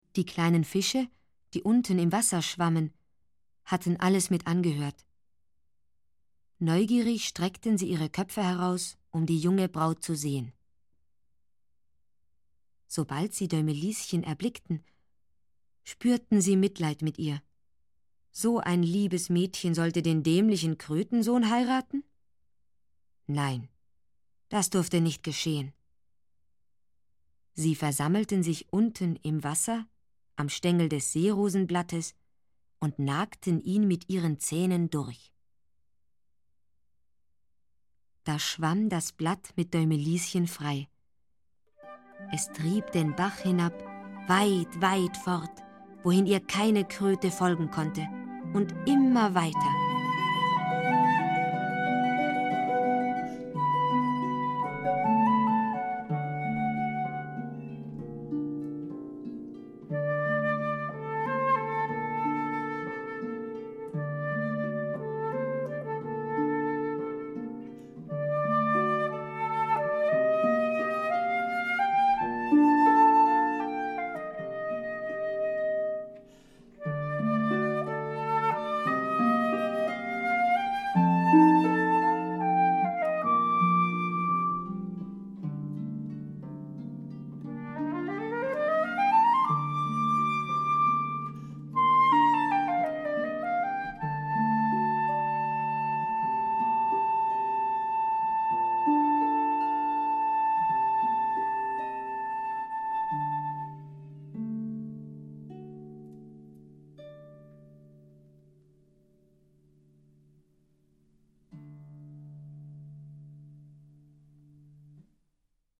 Für eine Erzählerin, Flöte und Harfe.